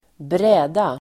Uttal: [²br'ä:da]